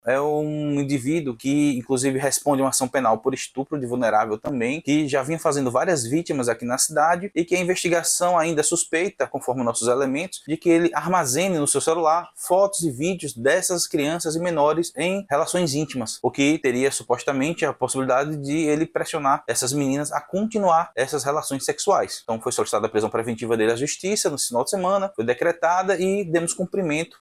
Ainda segundo o delegado, além do crime de estupro de vulnerável e aliciamento de outras menores, há suspeitas de que o autor armazenava vídeos íntimos das vítimas como forma de chantagem.